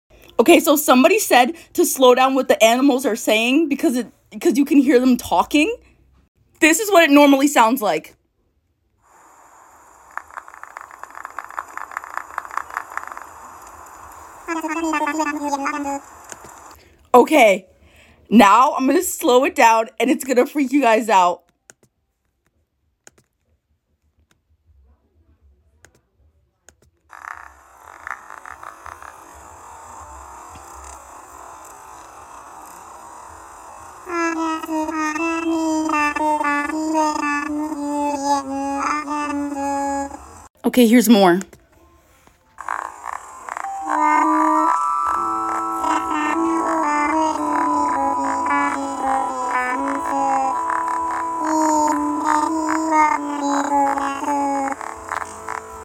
Here’s what animalese sounds like slowed down.
it sounds like a japanese person talking in english like it got the accent😭
so its a speed english but with japanese pronunciation
y'all can understand that? it just sounds like slower electronic gibberish that vaguely resembles words
Ohhh they pronounce parts of the words at a time with a japanese pronunciation